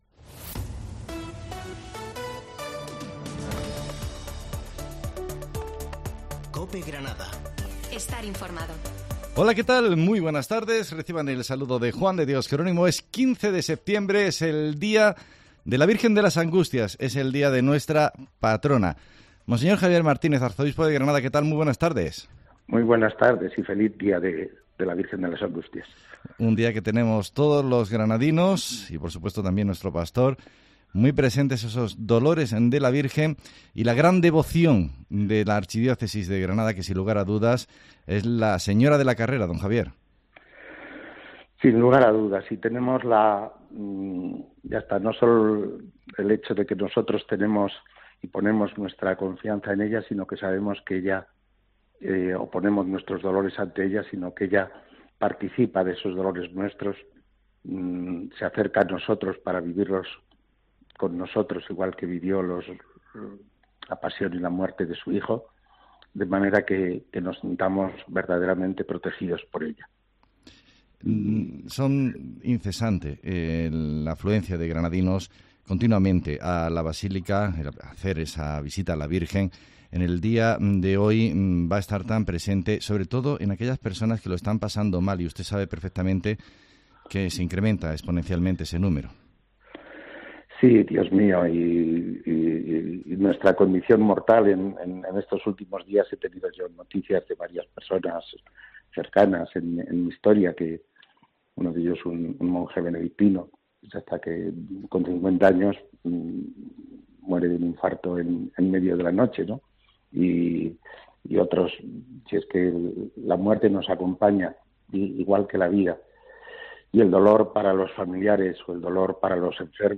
Entrevista con el arzobispo de Granada con motivo de la solemnidad de la Patrona